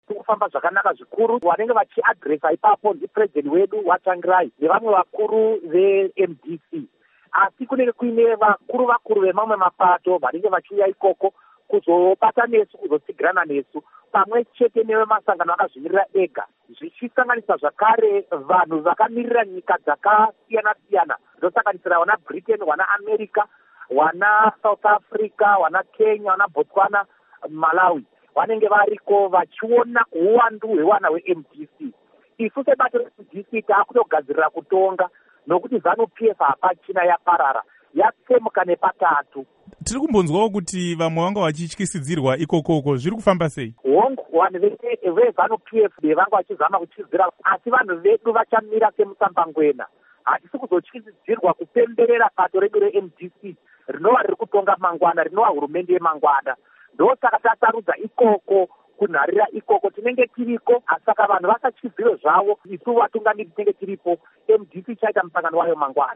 Hukuro naVaDouglas Mwonzora